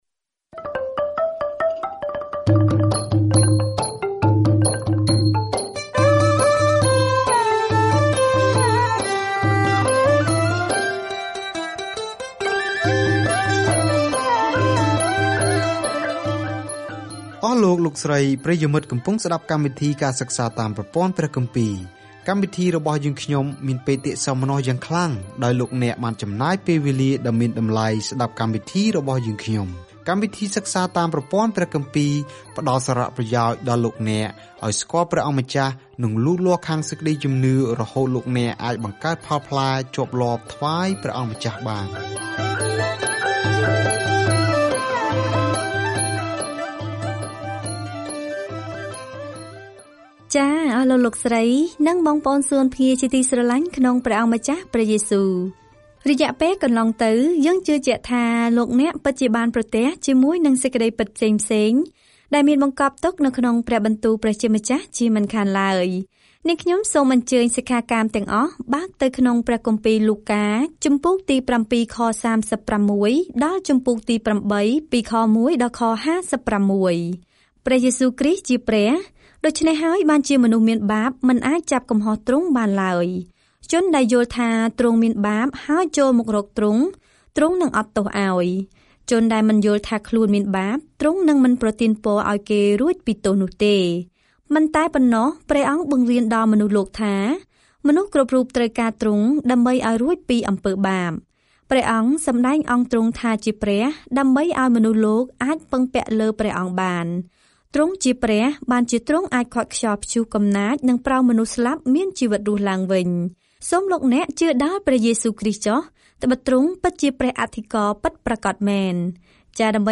ការធ្វើដំណើរជារៀងរាល់ថ្ងៃតាមរយៈលូកា នៅពេលអ្នកស្តាប់ការសិក្សាជាសំឡេង ហើយអានខគម្ពីរដែលជ្រើសរើសពីព្រះបន្ទូលរបស់ព្រះ។